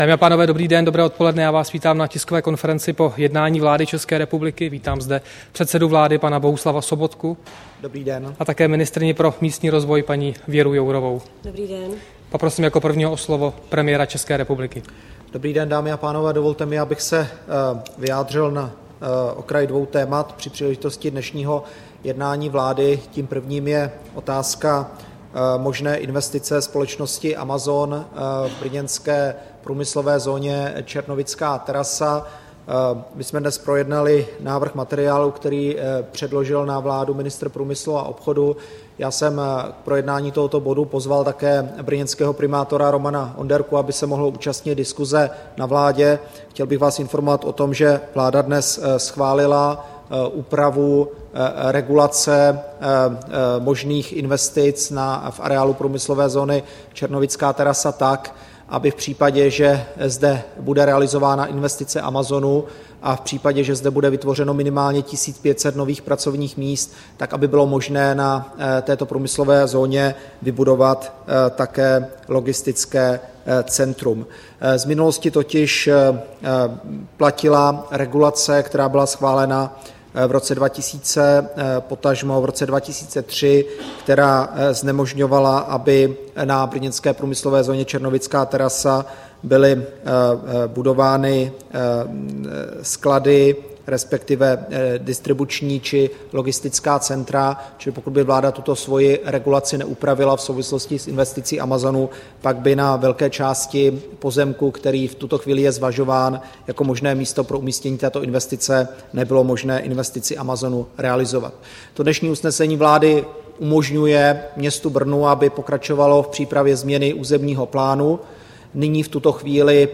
Tisková konference po jednání vlády, 12. května 2014